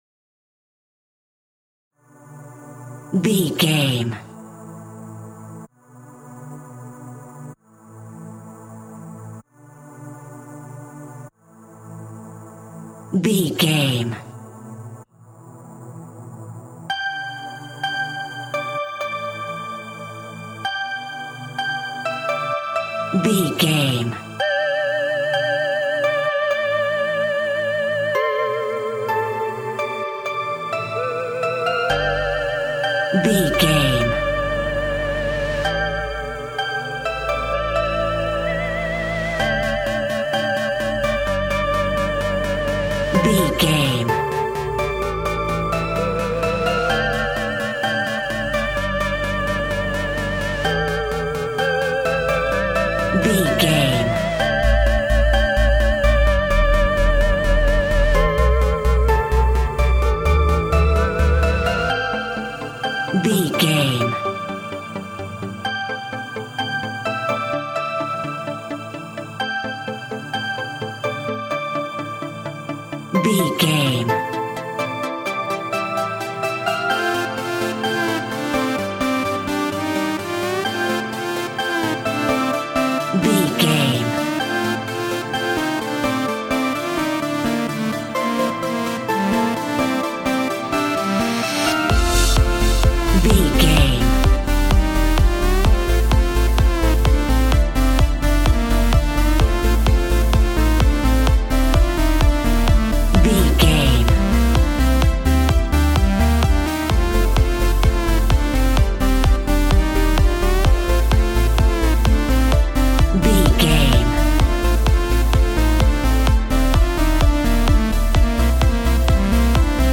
Clubbing Trance Sounds.
Ionian/Major
Slow
uplifting
futuristic
hypnotic
dreamy
peaceful
meditative
drum machine
synthesiser
trance
electronic
synth leads
synth bass